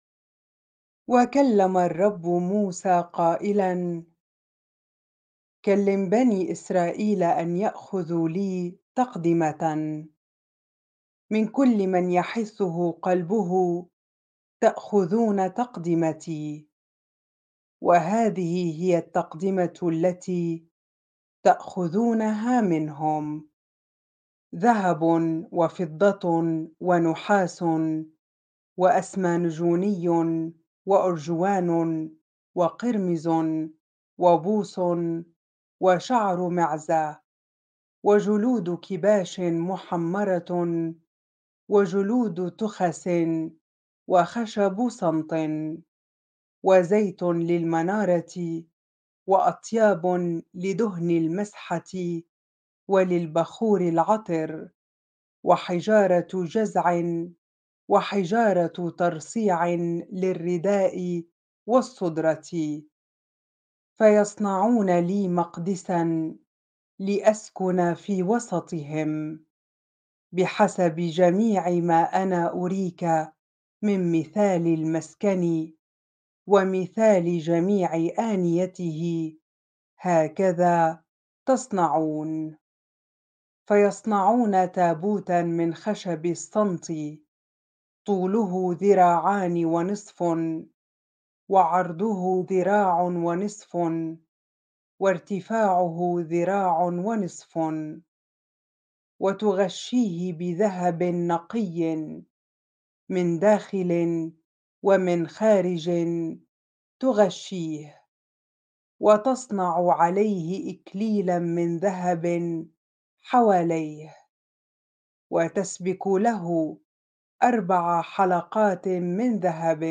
bible-reading-exodus-25 ar